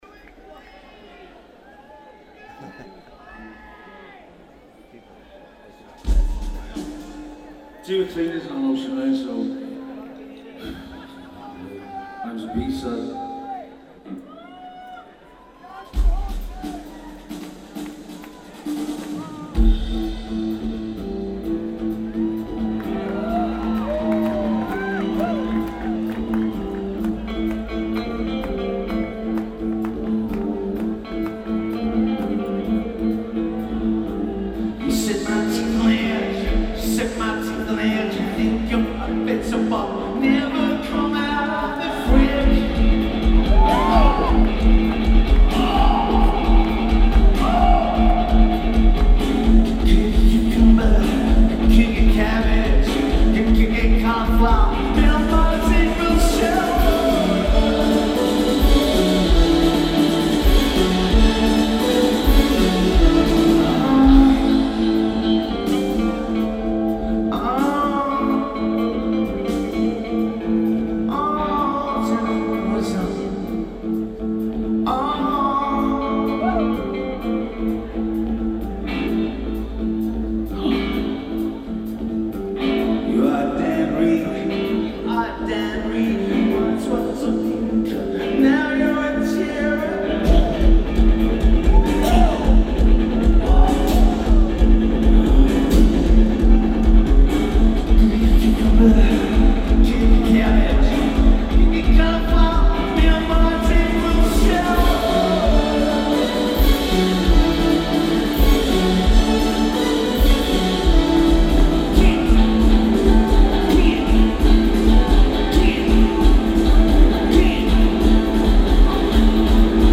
played in its entirety, accompanied by an orchestra.